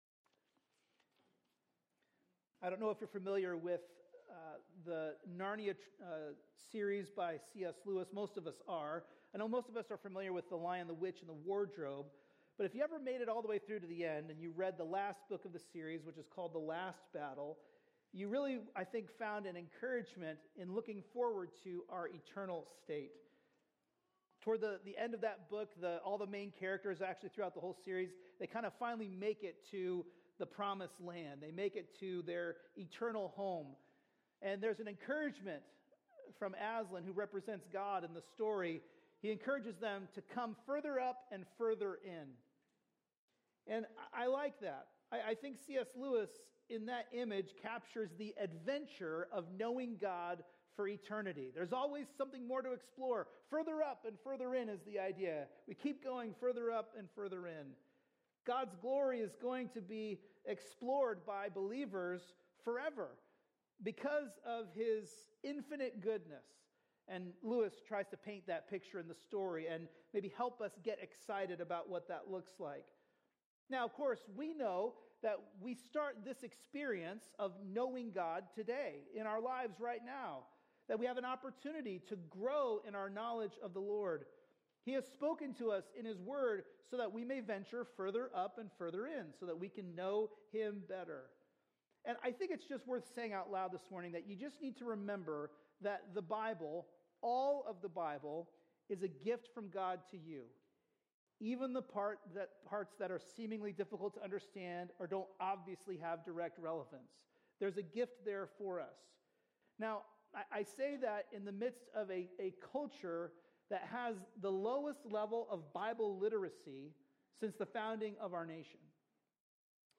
A message from the series "Ezra/Nehemiah." In Ezra 9:1-15, we learn that separation from the world is not optional, it is essential.